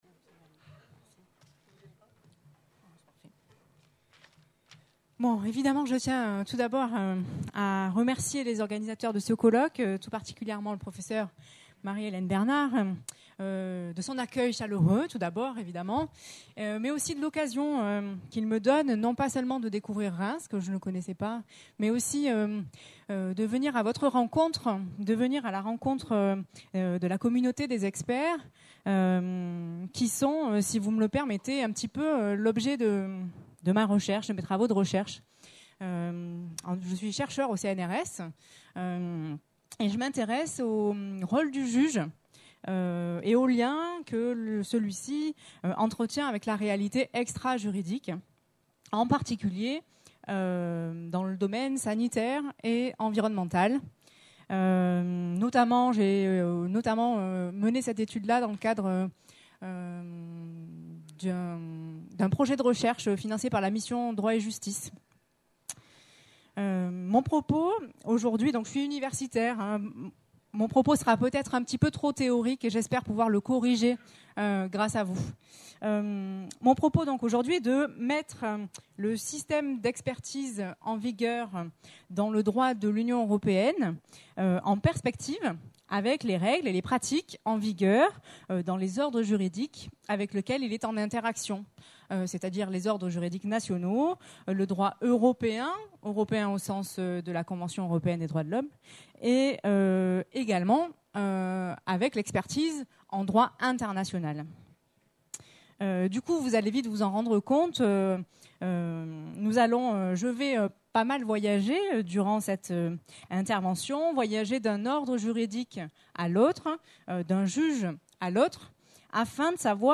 Colloque des Compagnies des Experts de Justice du Grand Est. Conférence : Modèle européen d'expertise : construction et influence sur les juridictions internationales.